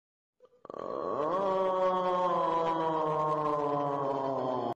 Plankton Groan Efecto de Sonido Descargar